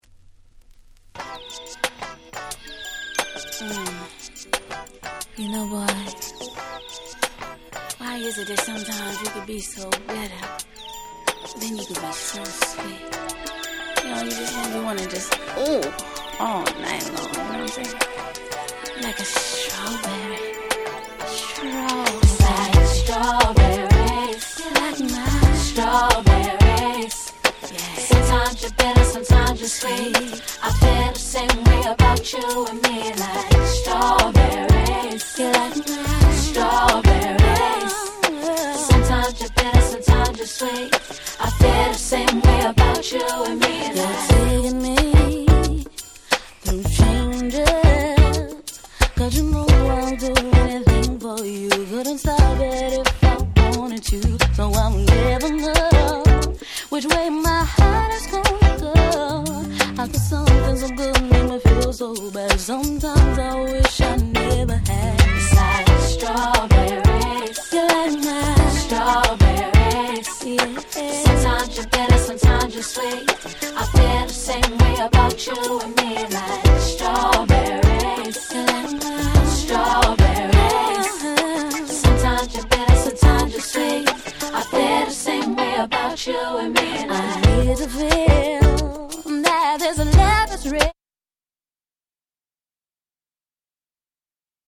90's R&B Classic !!